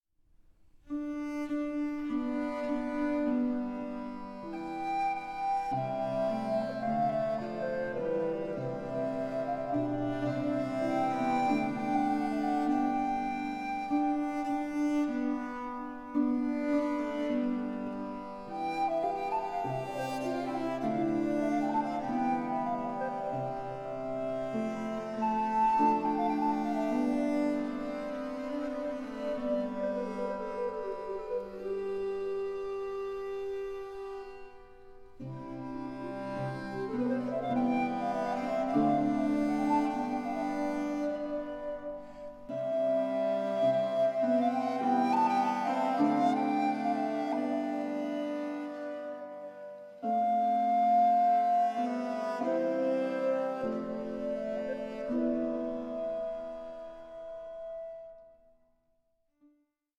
MELANCHOLIC SOLACE FOR TROUBLED TIMES